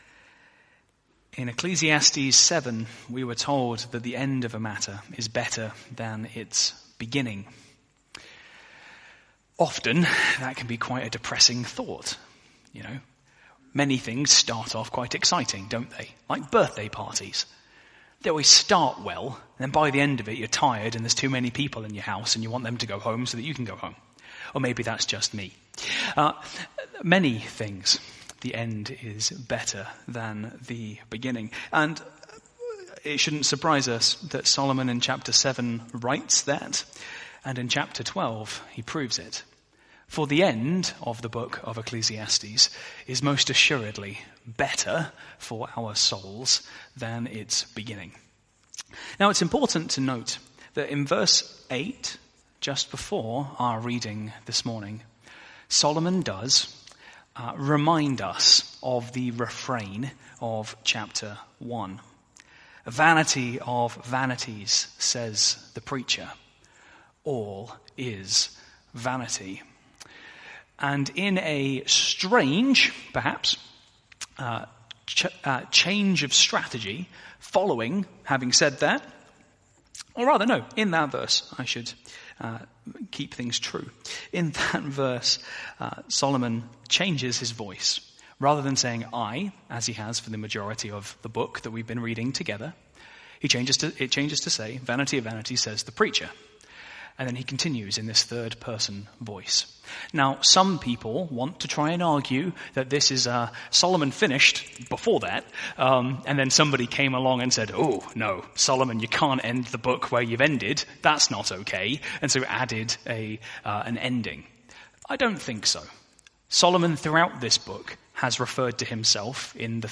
Sermon Series: Ecclesiastes